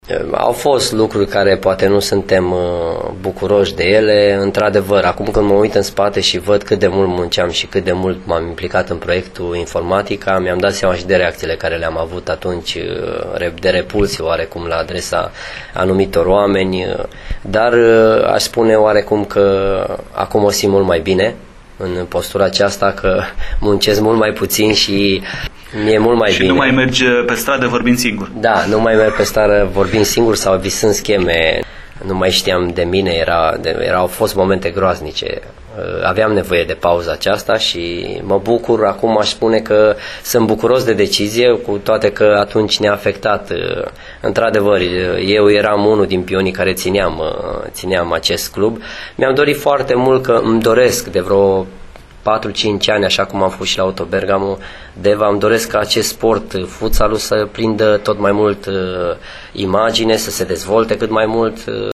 într-un interviu pentru Radio Timișoara.